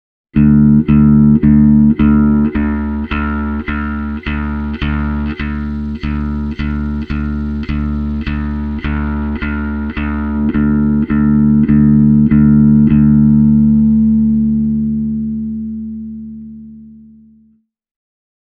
EBS-pedaalin särön määrä on vielä suhteellisen malttillinen, ja sen särösoundi miellyttävän orgaaninen, täysin vaille ärsyttävää fuzz-maista karheutta. Tone-potikka tarjoaa laajan valikoiman soundeja muhkeasta ohueen.
Seuraavassa ääninäytteessä esitän Tone-säätimen toimintaa, samalla kun Drive on avattu täysille:
tone-pot-sweep-e28093-drive-on-full-clean.mp3